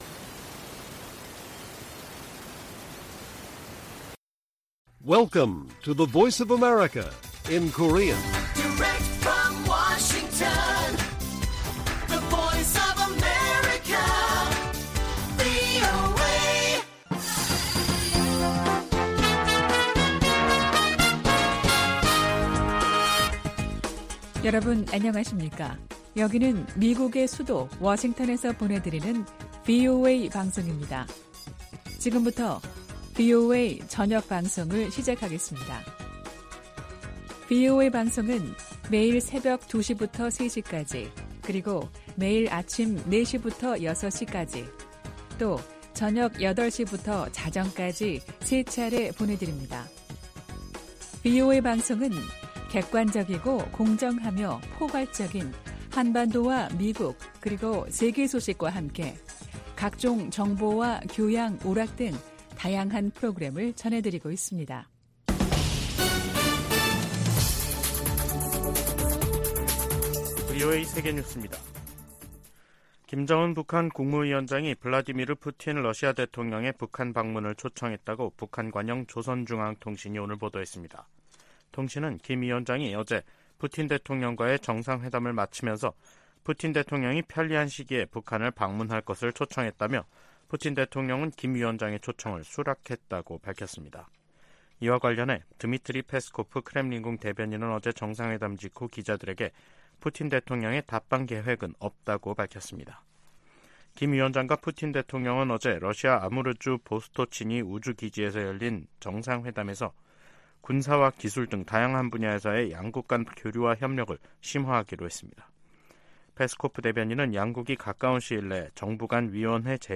VOA 한국어 간판 뉴스 프로그램 '뉴스 투데이', 2023년 9월 14일 1부 방송입니다. 러시아를 방문 중인 김정은 국무위원장이 푸틴 대통령의 방북을 초청한 것으로 북한 관영 매체가 보도했습니다.